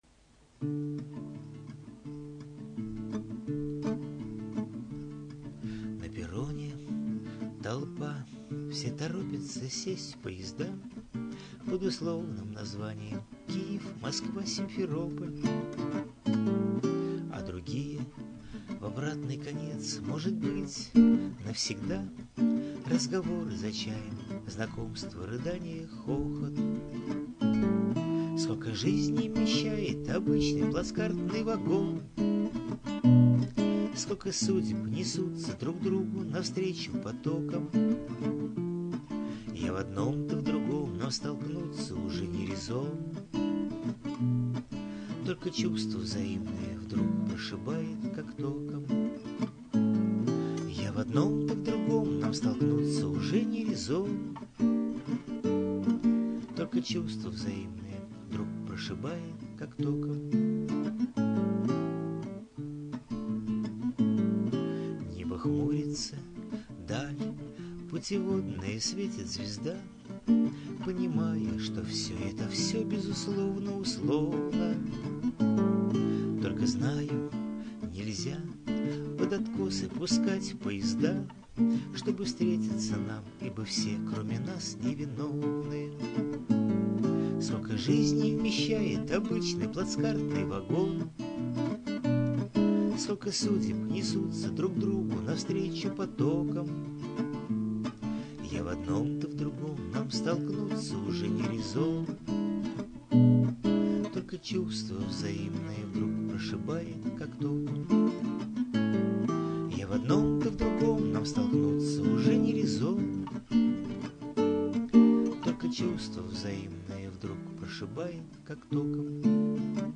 Я-то представляла её, песенку по-бабьи слезливой, заунывной, а ты изобразил чисто по-мужски, под стук колёс и вот этот последний аккорд - паровозным гудком 12
Я турист и мне ближе этот стук колес. smile